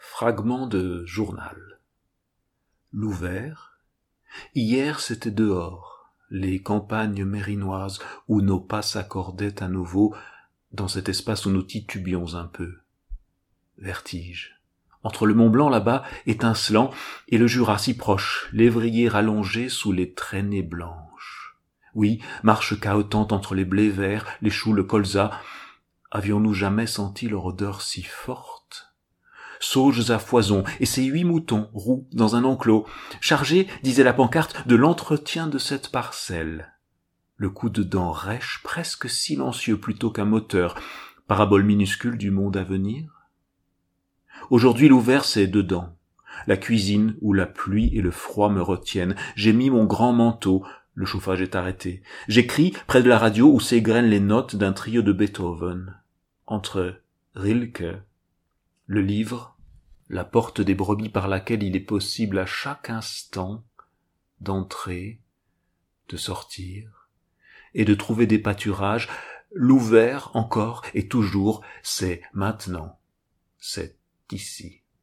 lue par